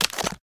creaking_step4.ogg